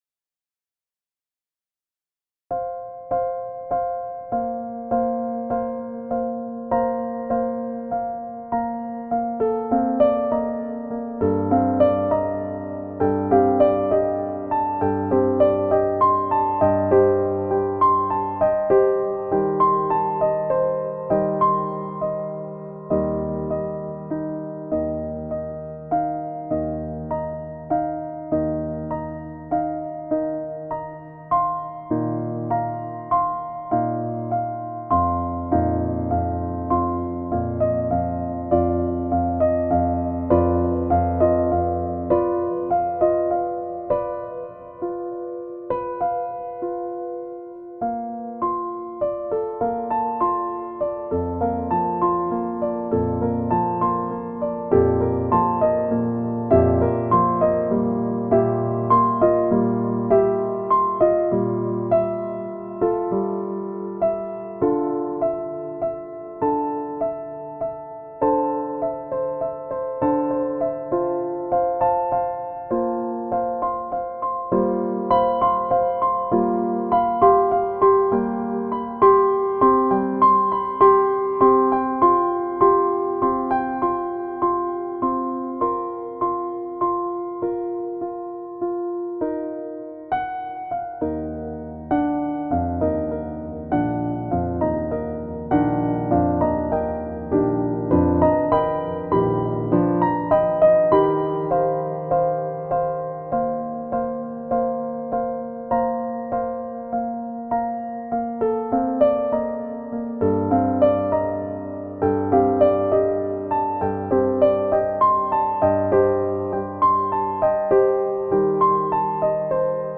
• Sonification by piano (physical modeling)
In this version, the velocity and sustain pedal are controlled depending on the harmonic development of the music generated from the mandala.